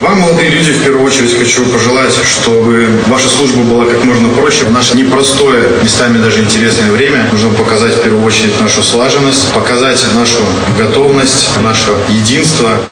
В Барановичах состоялся День призывника.
Повестки о призыве на срочную военную службу в торжественной обстановке получили около 270 юношей. Как отметил, обращаясь к присутствующим, военный комиссар города Барановичи, Барановичского и Ляховичского районов Алексей Синчук, перед военнослужащими сегодня стоят непростые задачи.